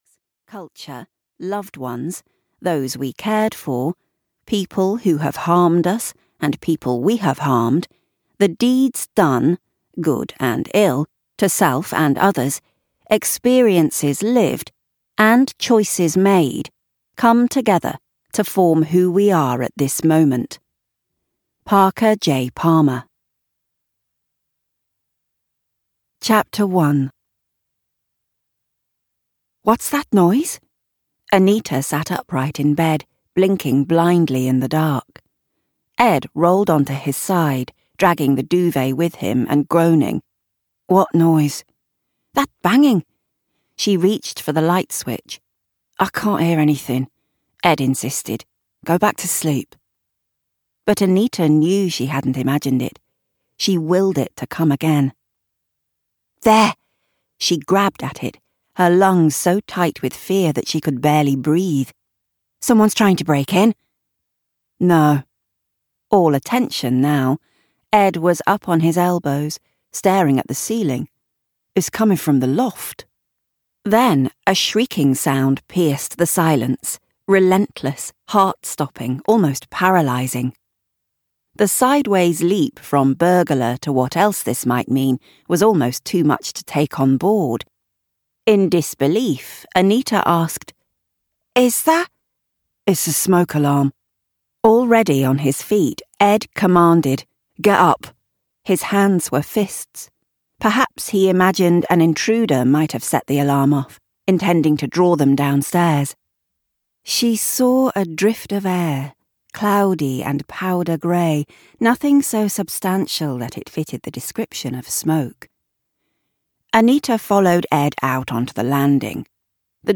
An Unknown Woman (EN) audiokniha
Ukázka z knihy